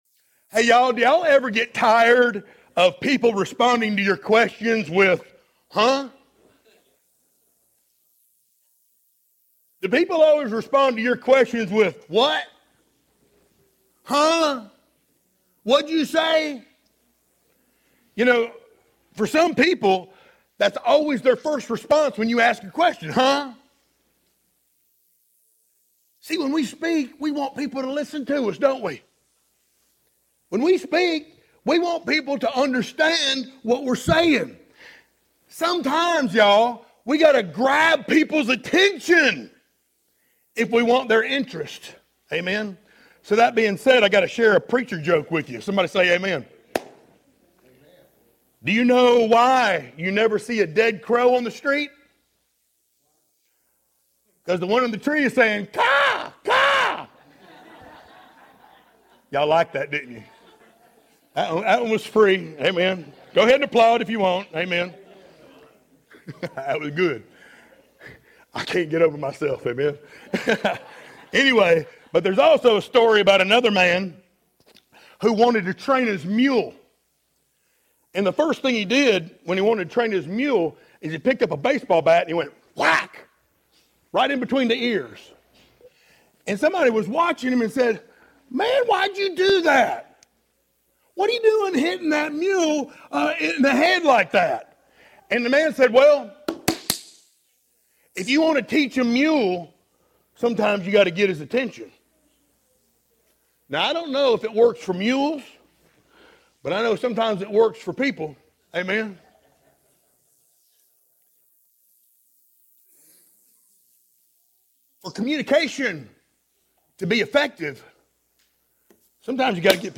sermons Passage: John 1:1-2 Service Type: Sunday Morning Download Files Notes Topics